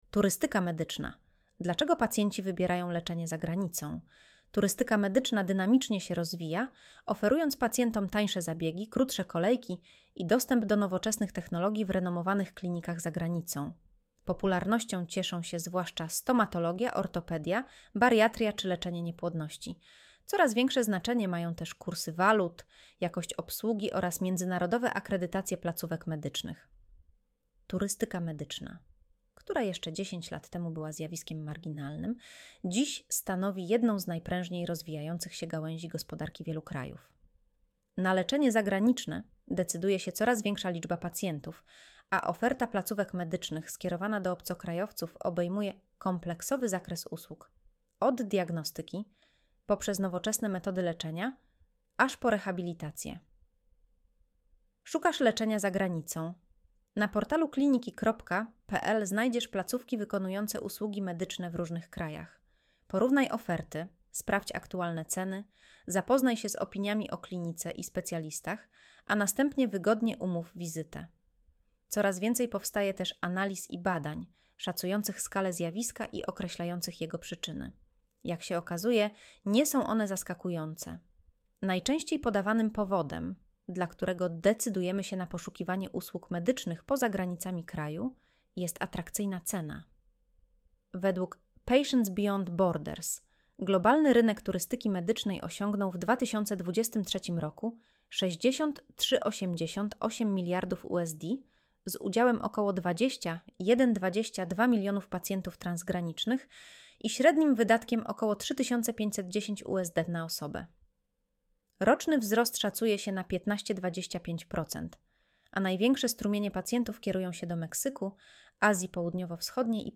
Audio wygenerowane przez AI, może zawierać błędy